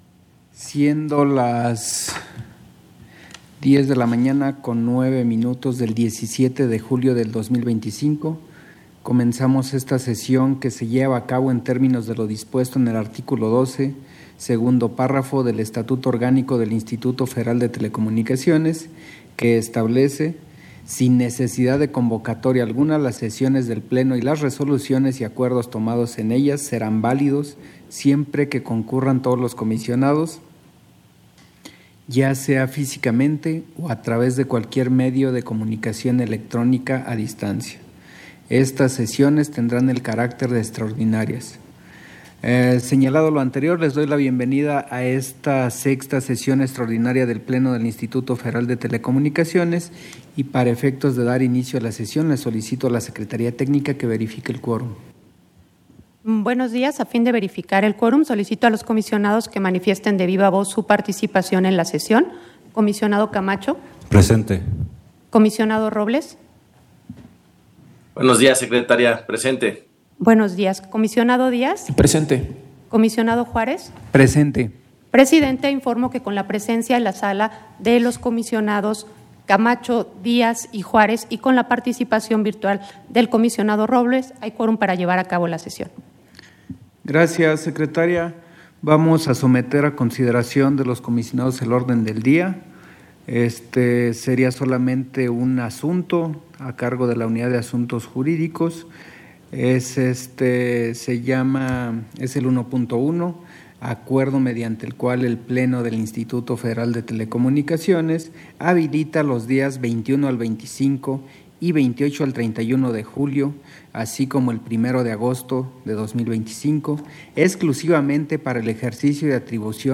Audio de la sesión